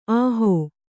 ♪ āhō